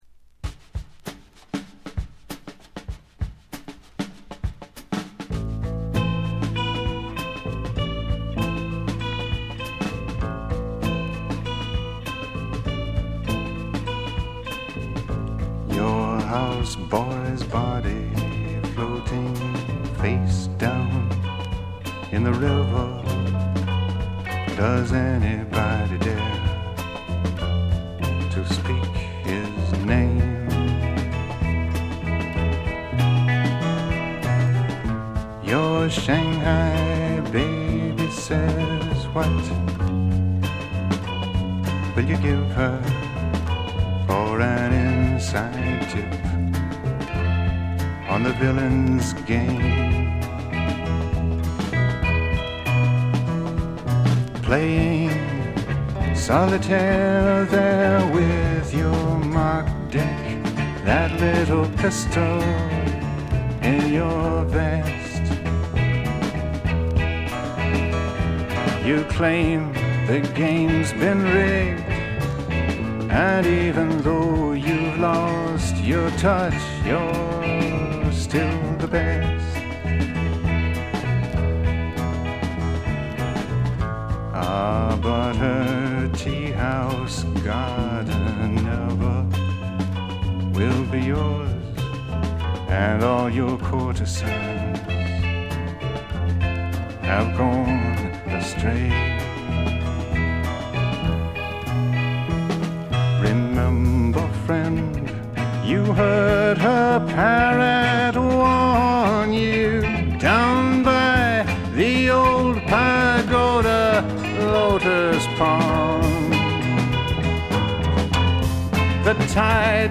ホーム > レコード：米国 SSW / フォーク
軽微なチリプチ程度。
暗い情念が渦巻くような迫力はなかなかのもので、アシッド・フォーク、サイケ好きも心を持っていかれると思います。
試聴曲は現品からの取り込み音源です。